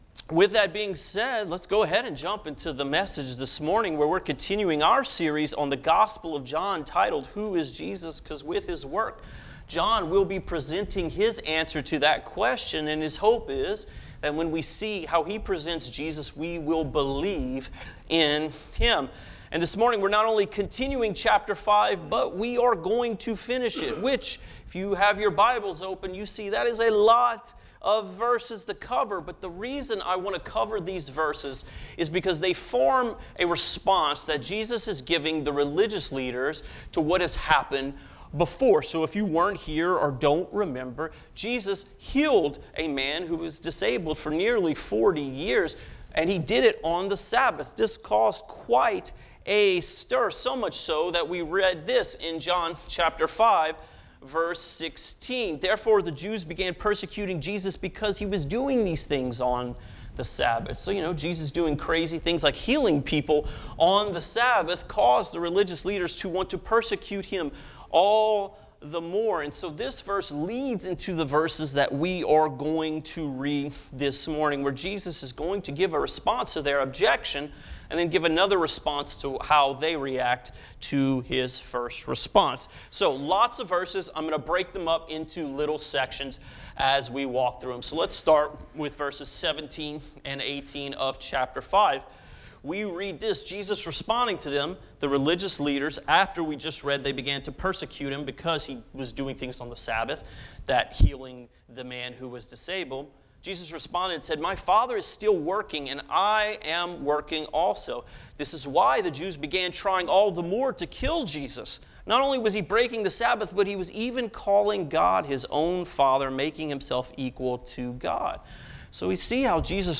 Sermons | Fellowship Baptist Church